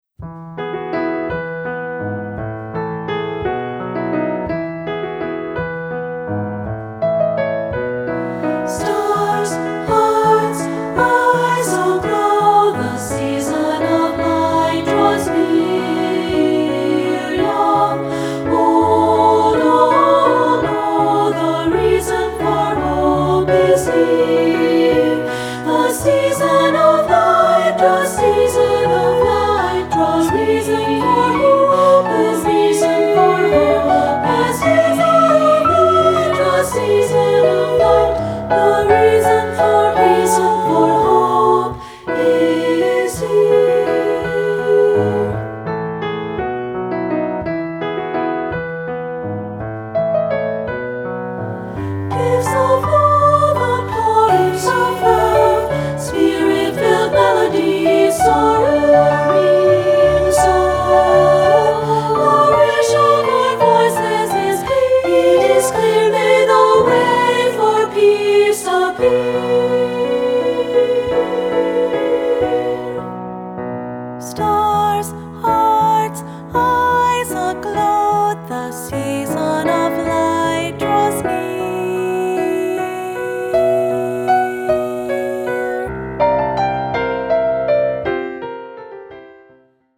Choral Christmas/Hanukkah